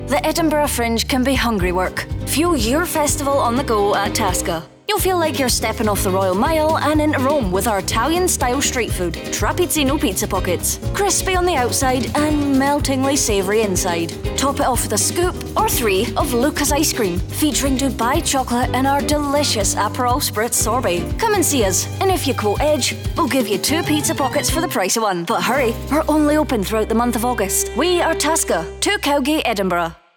Weiblich
Englisch (Britisch)
Englisch (Schottisch)
Kommerzielle Demo